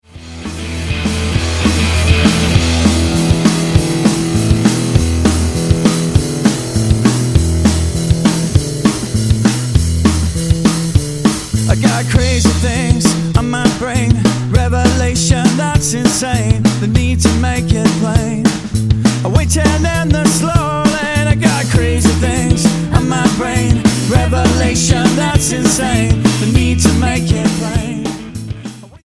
surf rockers
Style: Rock